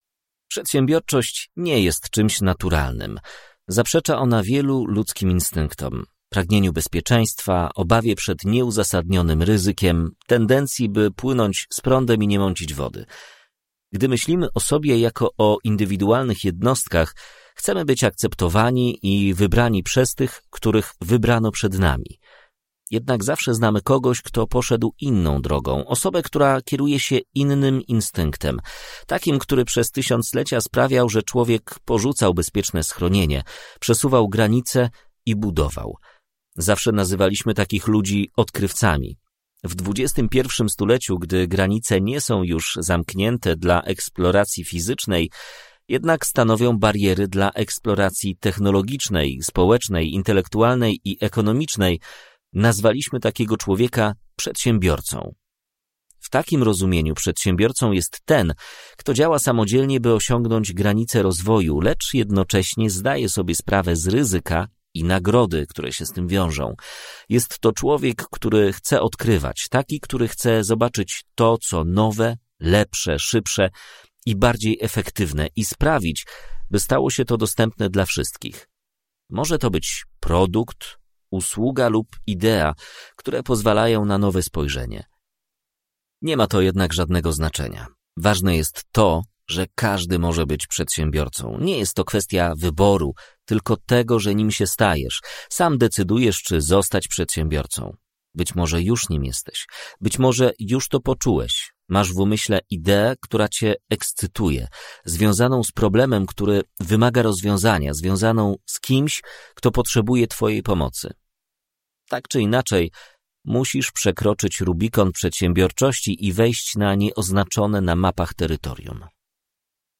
AUDIOBOOK (mp3)